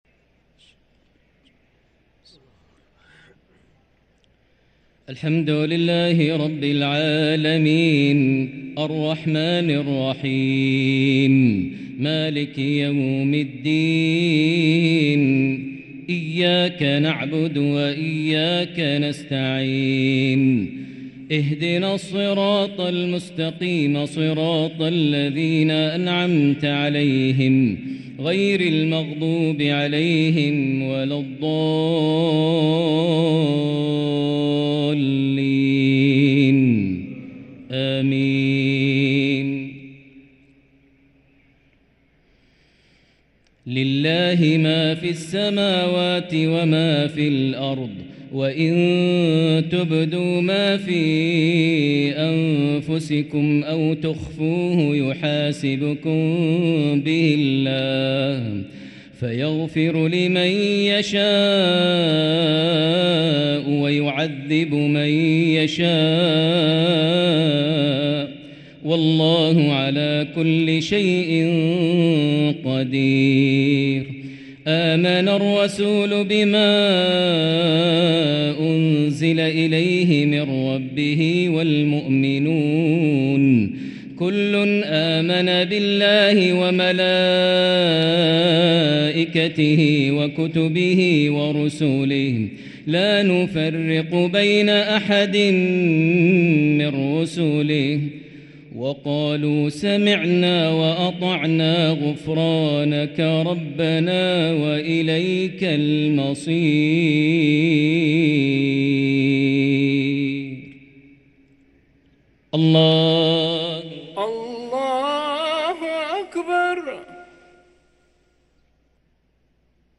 تلاوة جميلة لخواتيم سورة البقرة | عشاء الاثنين 5-9-1444هـ > 1444 هـ > الفروض - تلاوات ماهر المعيقلي